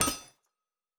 pgs/Assets/Audio/Fantasy Interface Sounds/Blacksmith 05.wav at master
Blacksmith 05.wav